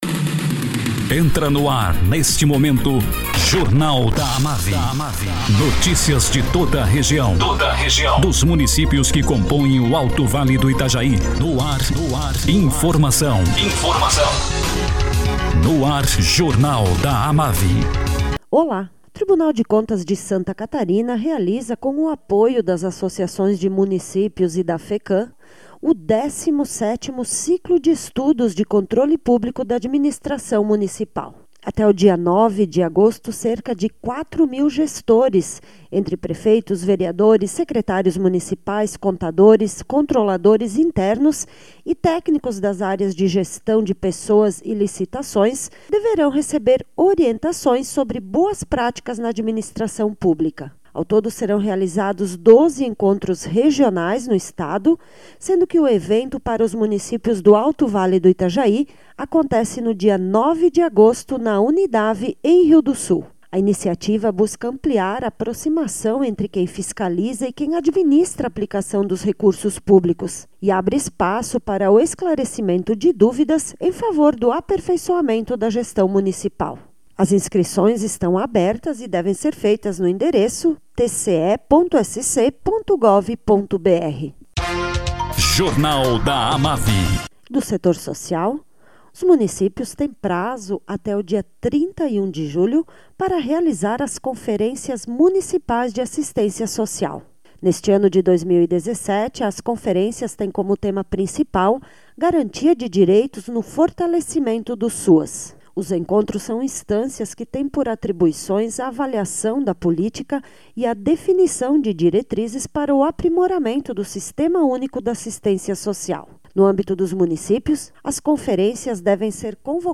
Prefeito de Rio do oeste, Humberto Pessatti, convida a população do Alto Vale para a XIX FEPOL- Festa Estadual da Polenta, que inicia hoje e se estende até domingo.